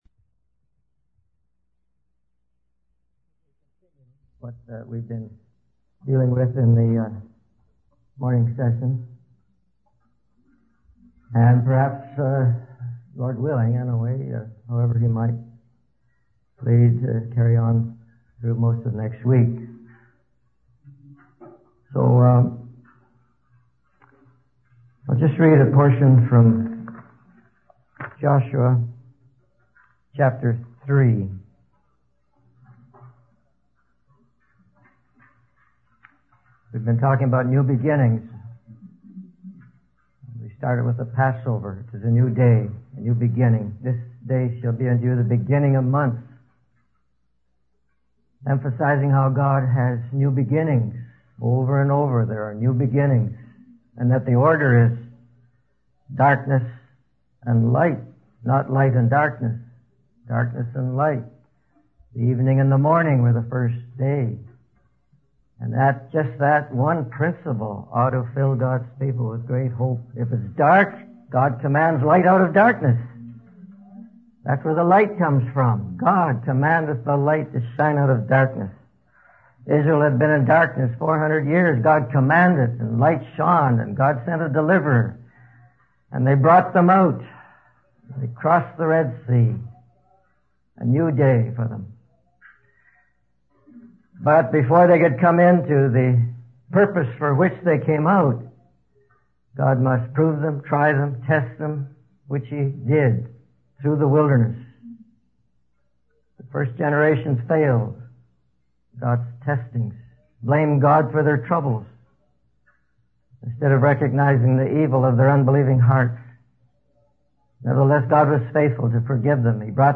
In this sermon, the preacher discusses the story of the Israelites and their journey from darkness to a new day. He emphasizes that God tested the first generation in the wilderness, but they failed due to their unbelieving hearts. However, God forgave them and brought up a new generation to enter the promised land.